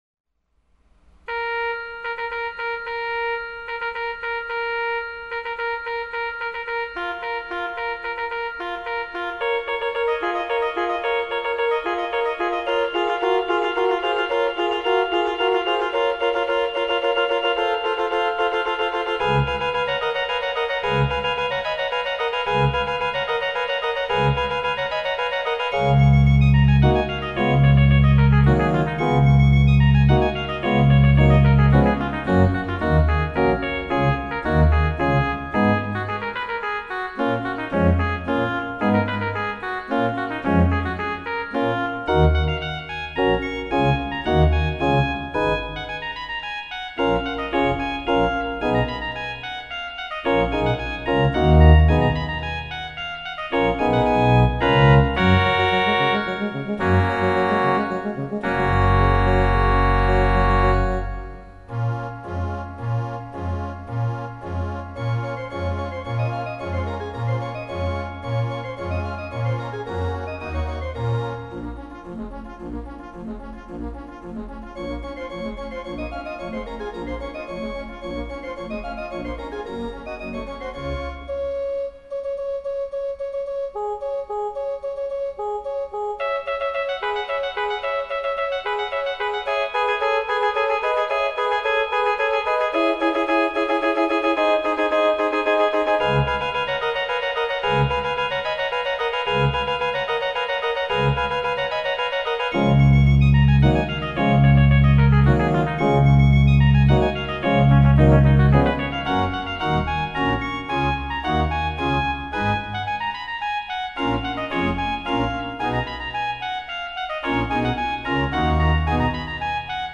Here you can listen to screeching renditions of songs you will barely recognize!
Procession of the Nobles from Mlada by Rimsky Korsakov - this is a Lemare-ish organ transcription I did years ago and is performed by my laptop computer playing the 1928 E. M. Skinner sample set.